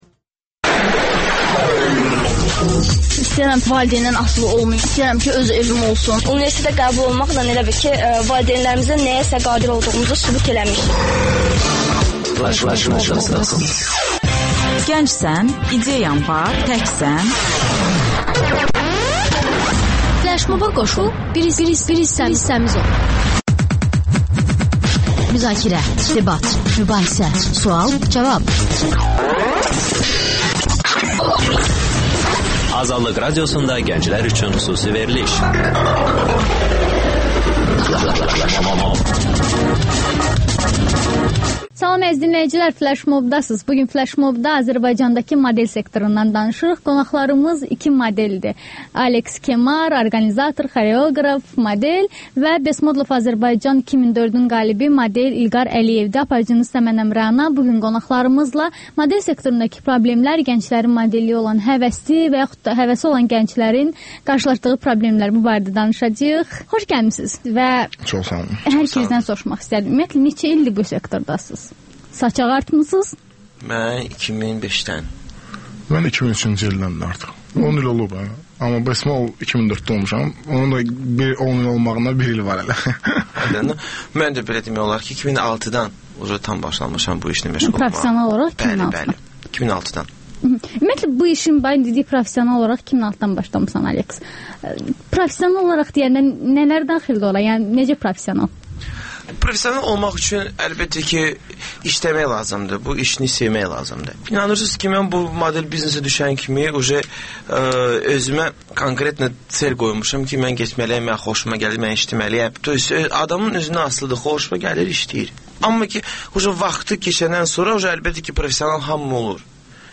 Qonaqlar: xoreoqraf, model